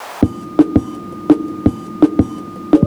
FXBEAT05-R.wav